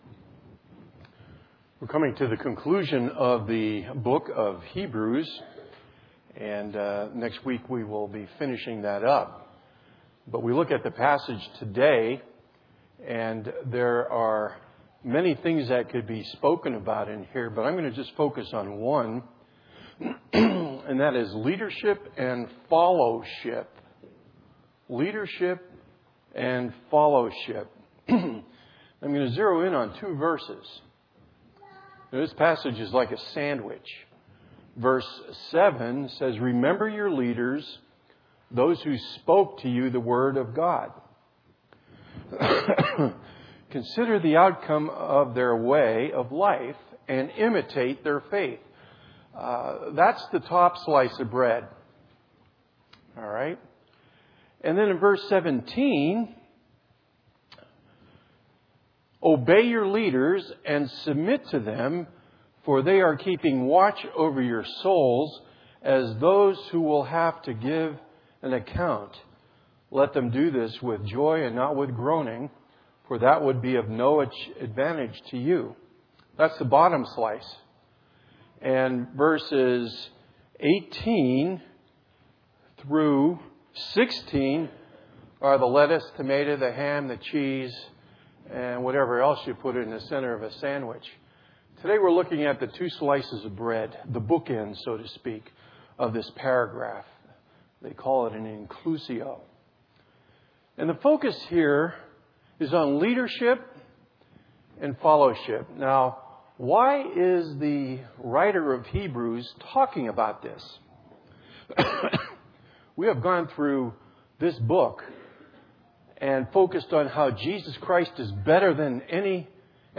A Collection of 2016 Sermons from Windsor Baptist Chruch